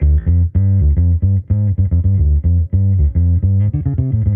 Index of /musicradar/sampled-funk-soul-samples/110bpm/Bass
SSF_PBassProc1_110D.wav